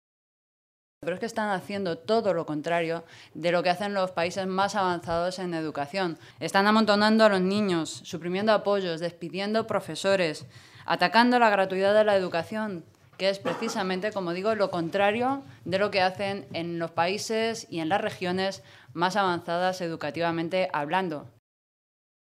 Carmen Rodrigo, portavoz de Educación del Grupo Parlamentario Socialista
Cortes de audio de la rueda de prensa